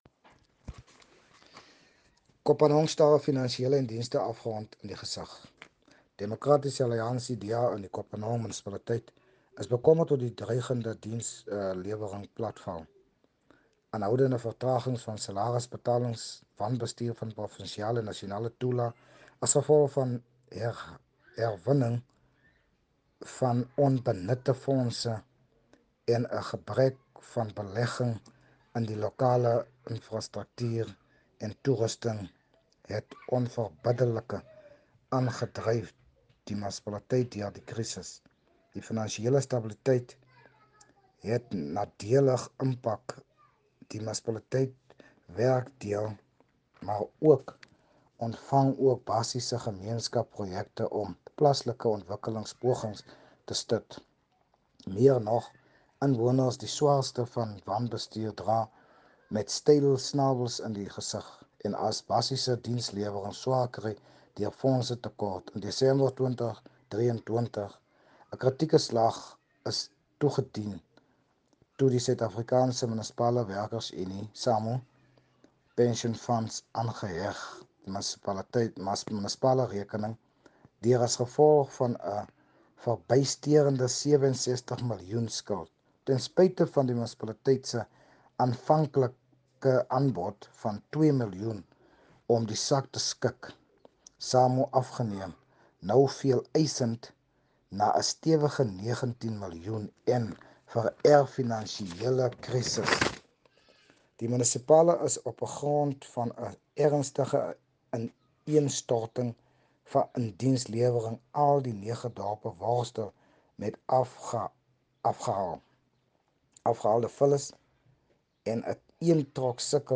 Afrikaans soundbites by Cllr Richard van Wyk and